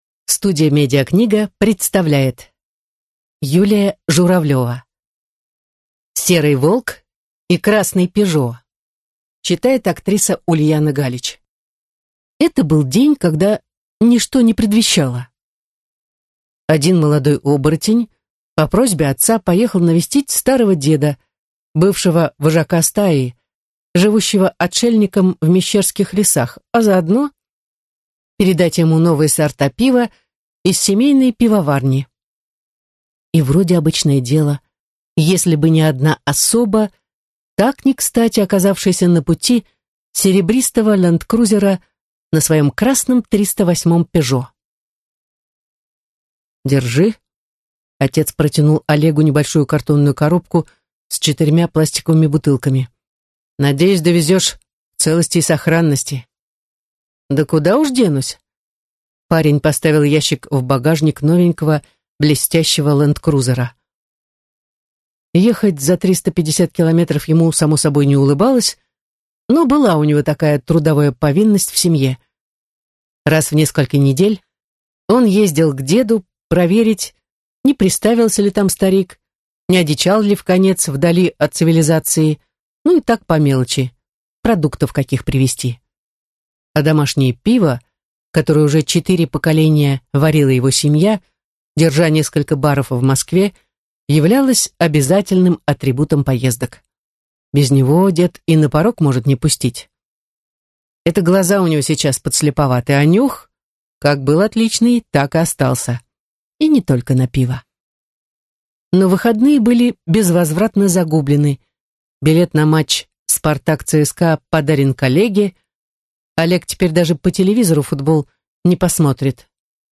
Аудиокнига Серый волк и красный «Пежо» | Библиотека аудиокниг